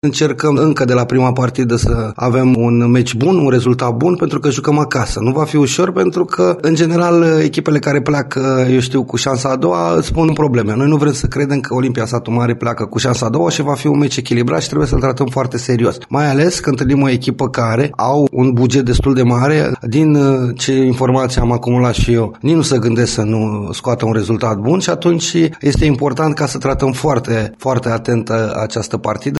Antrenorul principal Flavius Stoican își dorește primele puncte încă de la acest meci: